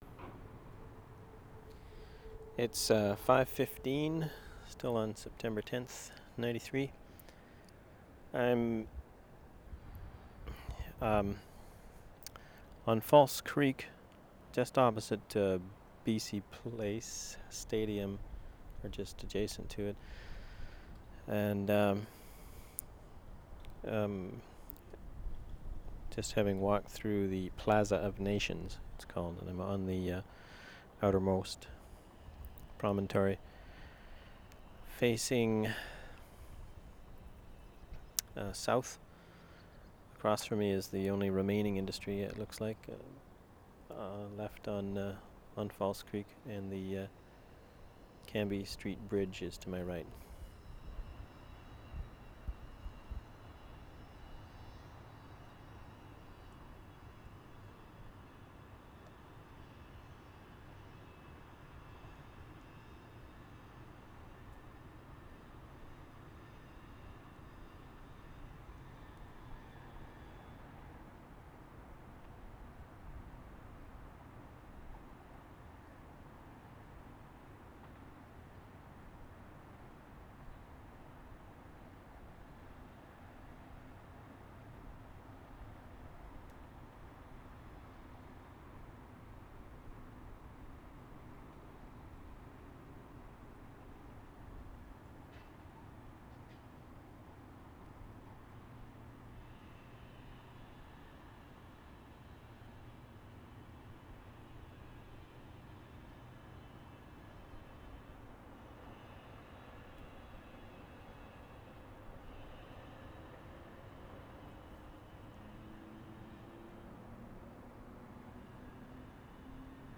WORLD SOUNDSCAPE PROJECT TAPE LIBRARY
FALSE CREEK - SEPT 10, 12, 1993
BC Place/Plaza of Nations 5:37